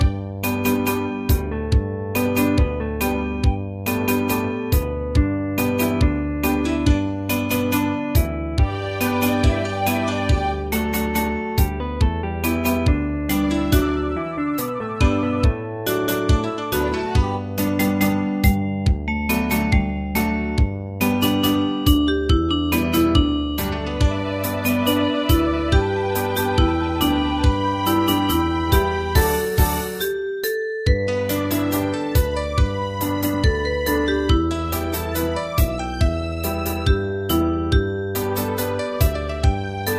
大正琴の「楽譜、練習用の音」データのセットをダウンロードで『すぐに』お届け！
Ensemble musical score and practice for data.
Tags: Japanese , Kayokyoku Enka .